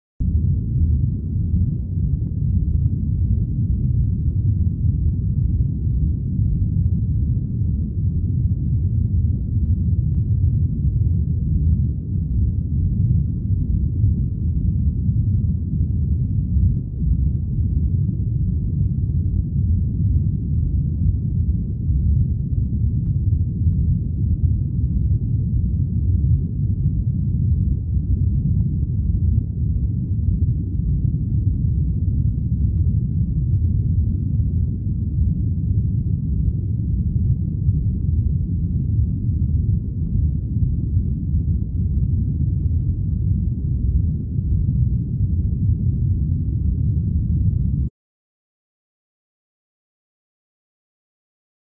LowFreqEarthqkRumb PE194601
Low Frequency Earthquake Rumble With Stereo Motion.